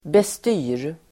Uttal: [best'y:r]